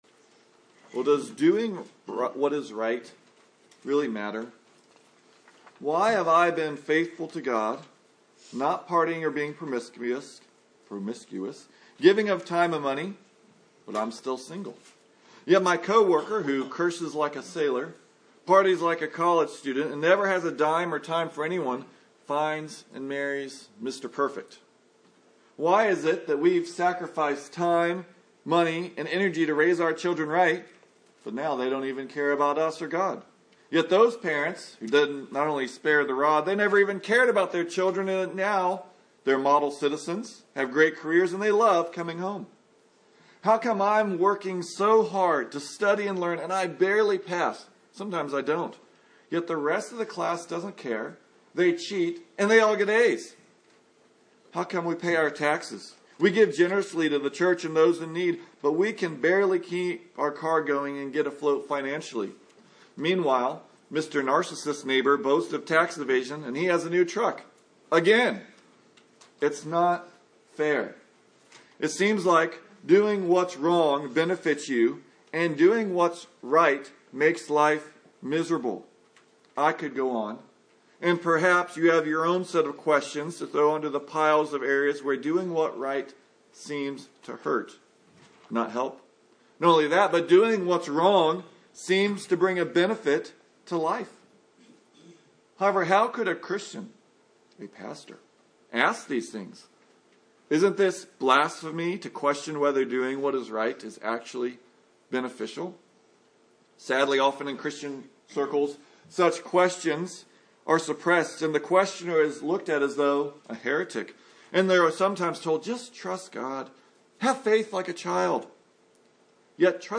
Psalm 73 Service Type: Sunday Morning Even believers have times where we wrestle and wonder